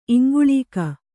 ♪ iŋguḷīka